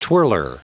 Prononciation du mot twirler en anglais (fichier audio)
Prononciation du mot : twirler